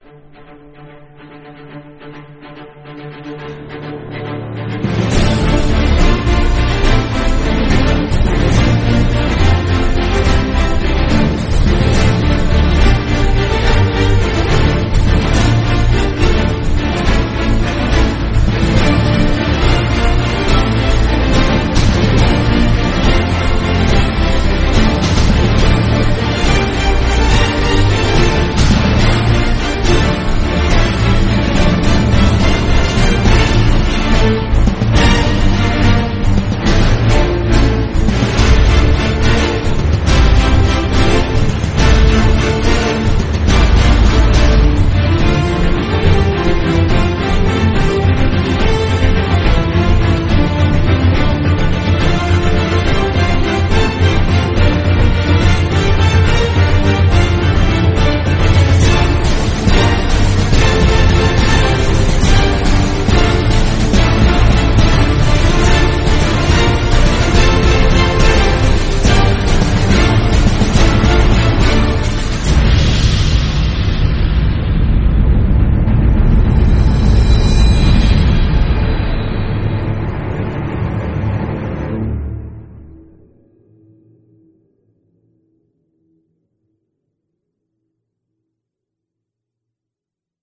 Orchestral, Soundtrack